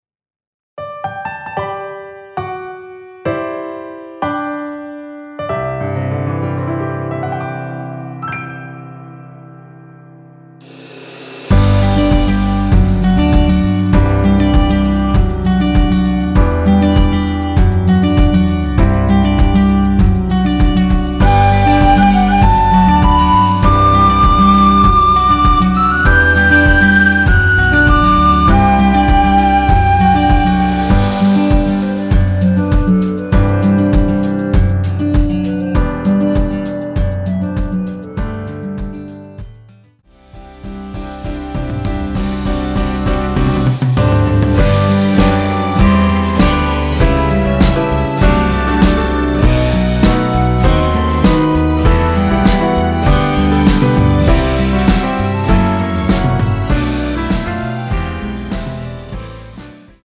원키에서(-1)내린 멜로디 포함된 MR입니다.(미리듣기 확인)
멜로디 MR이라고 합니다.
앞부분30초, 뒷부분30초씩 편집해서 올려 드리고 있습니다.
중간에 음이 끈어지고 다시 나오는 이유는